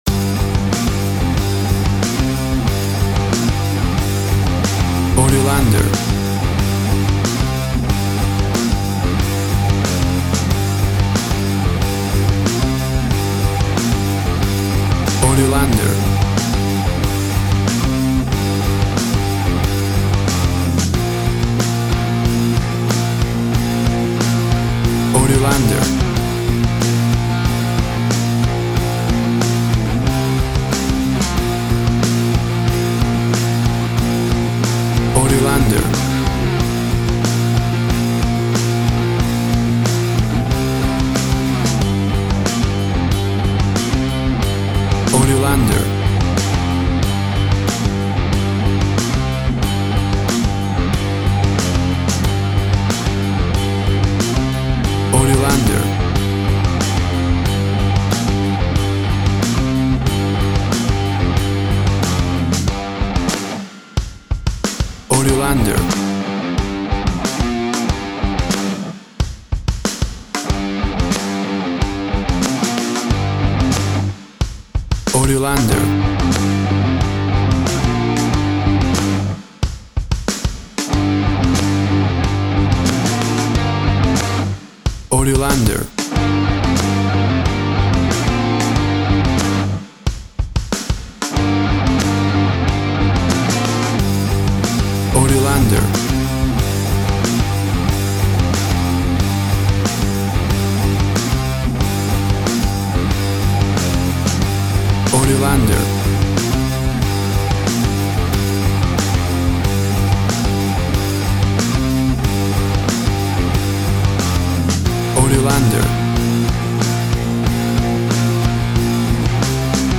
1970´s Classic heavy metal Rock.
Tempo (BPM) 93